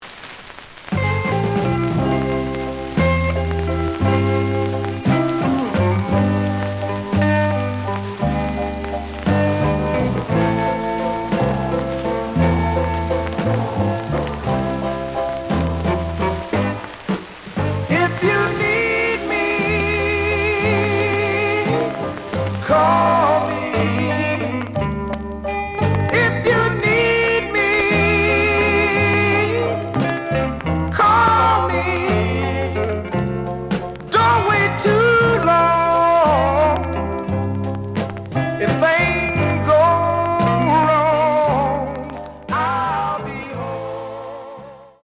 The audio sample is recorded from the actual item.
●Genre: Soul, 60's Soul
Noticeable noise on A side.)